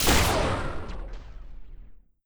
plasma_rifle_shot.wav